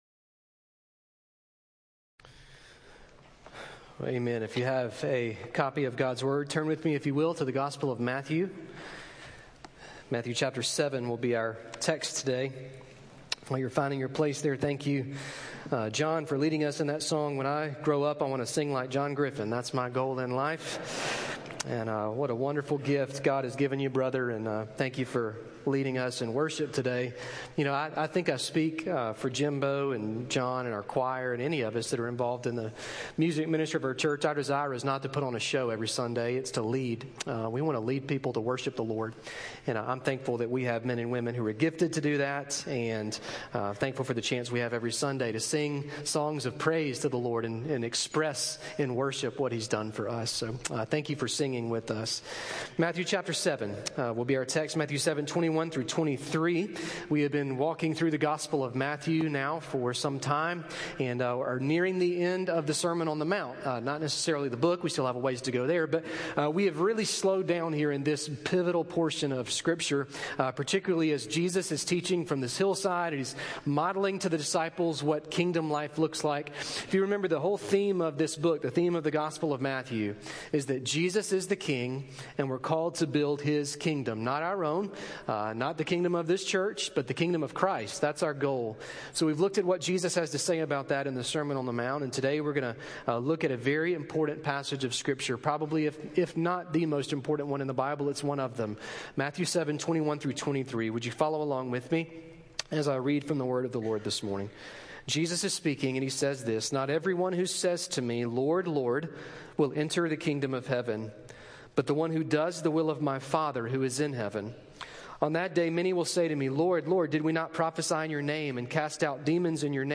A message from the series "Other."